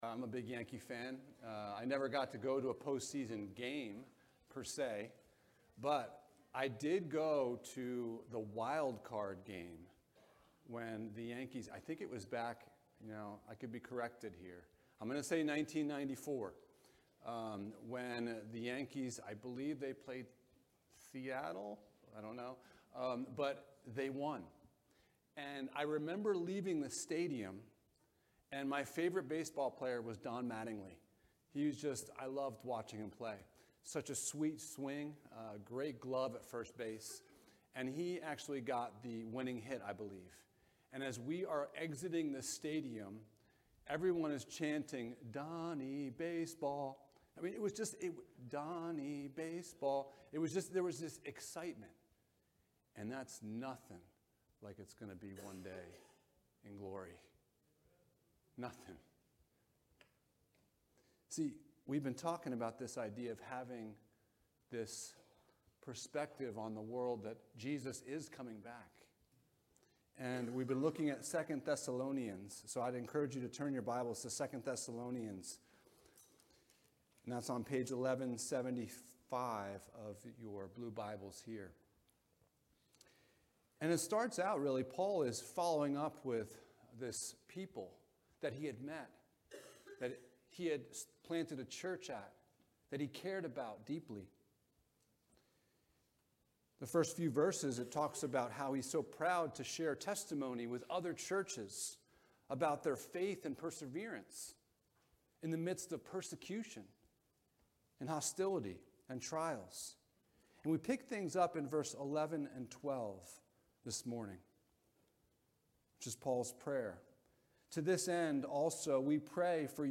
Living in Light of Christ's Return Passage: 2 Thessalonians 1: 11-12 Service Type: Sunday Morning « Realities of the Return of Jesus What Are You Waiting For?